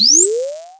まずは、せっかくなのでジャンプの効果音をブラウザで動くシンセサイザーJFXRで作ってみましょう。
つぎは「大ジャンプ」の効果音として下記設定をして、[Export]ボタンからJump1.wavというファイル名DXライブラリのプロジェクトのSEフォルダに保存しましょう。
Jump1.wav